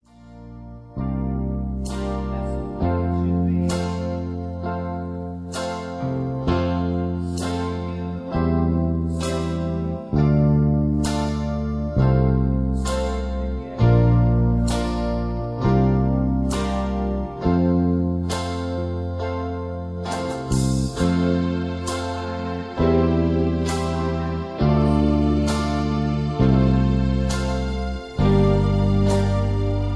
(Version-3, Key-F) Karaoke MP3 Backing Tracks